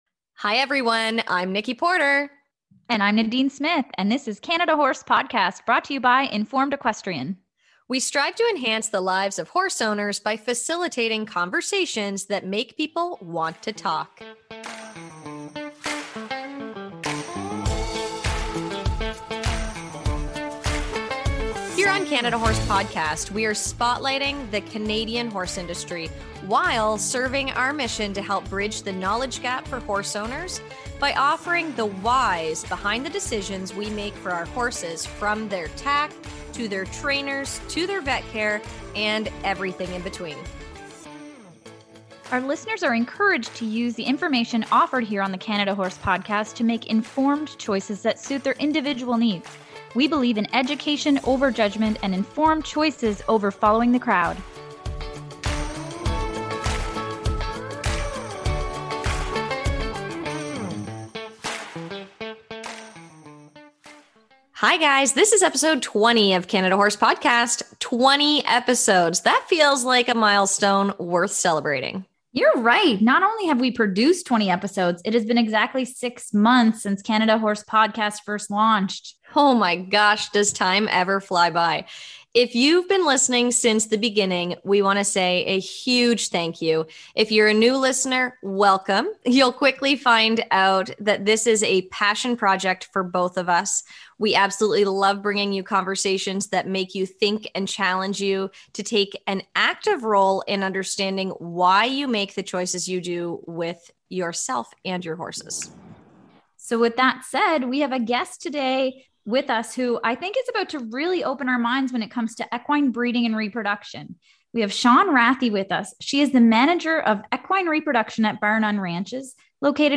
In this episode we ask the questions some may never ask in fear of thinking they should have known. Our lack of breeding experience allows us to have this conversation making no assumptions of what our listeners know and leads to some great, and entertaining, conversation.